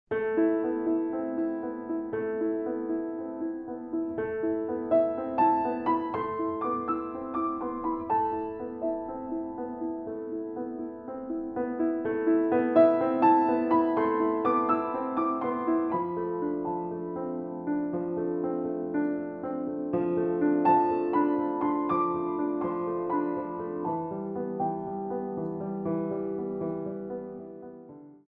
Improv